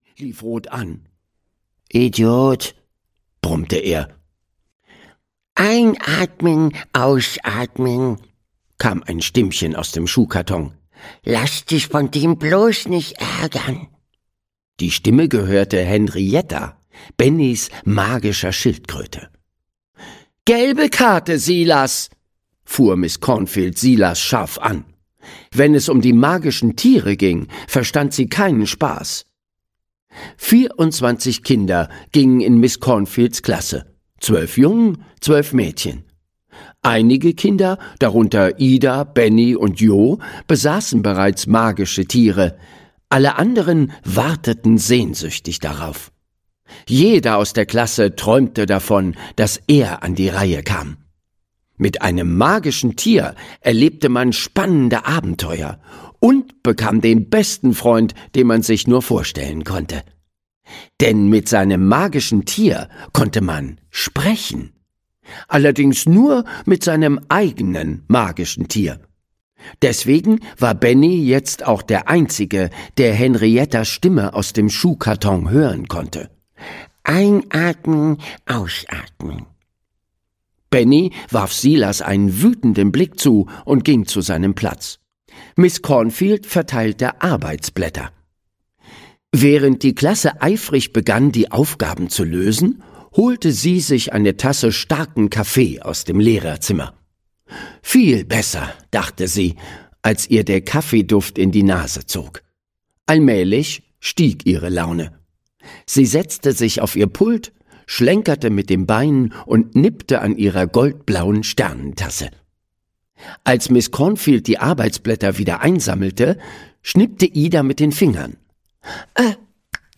Die Schule der magischen Tiere 4: Abgefahren! - Margit Auer - Hörbuch